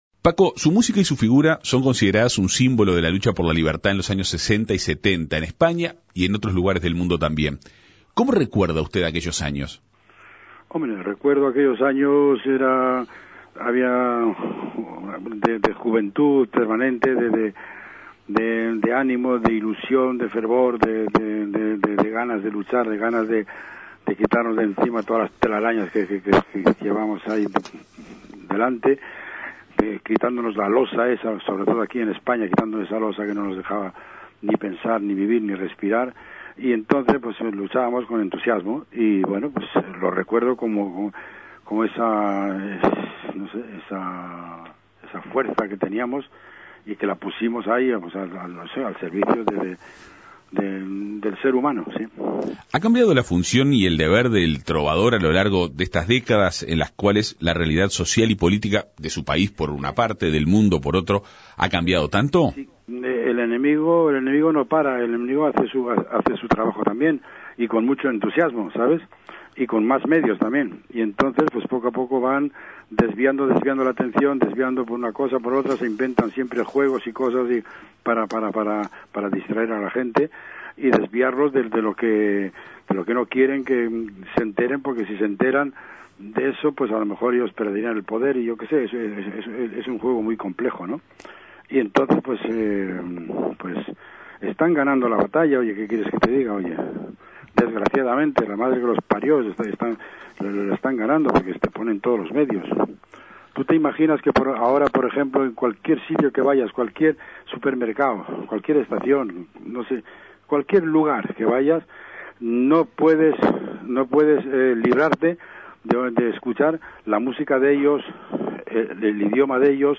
El jueves 9 de setiembre, este trovador español subirá al escenario del Cine Teatro Plaza con sus canciones. El compositor dialogó en la Segunda Mañana de En Perspectiva.